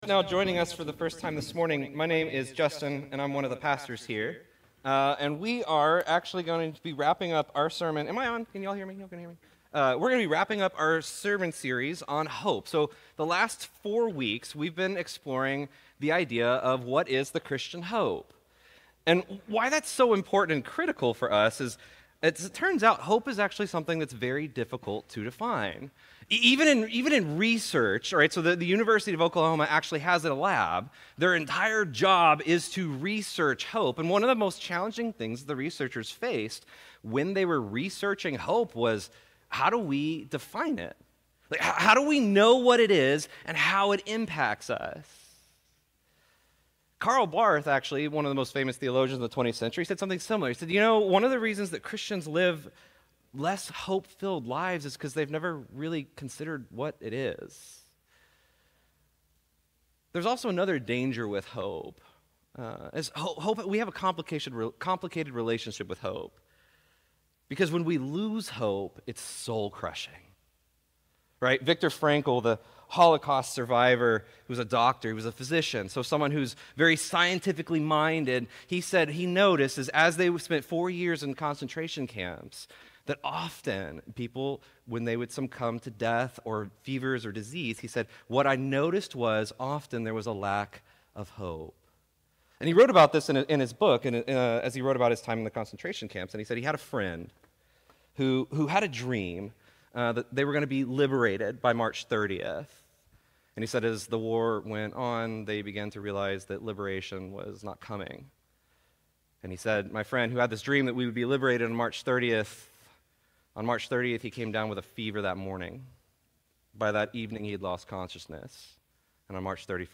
Restore Houston Church Sermons How to Find Hope Again After You’ve Lost It Oct 29 2024 | 00:34:35 1x 00:00 / 00:34:35 Subscribe Share Apple Podcasts Overcast RSS Feed Share Link Embed